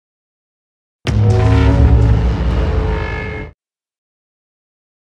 hq-avengers-thanos-infinity-gauntlet-sound-effect_bP9qeDB.mp3